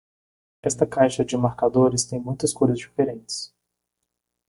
Pronunciado como (IPA) /ˈko.ɾis/